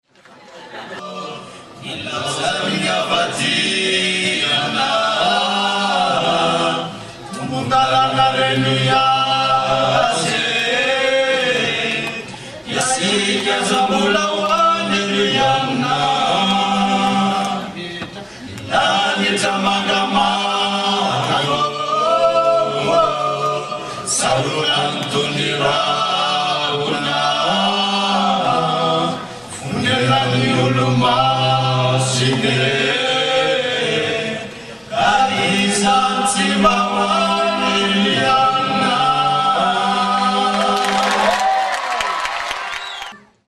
Voici quelques extraits audio de la soirée récréative !
chant malgache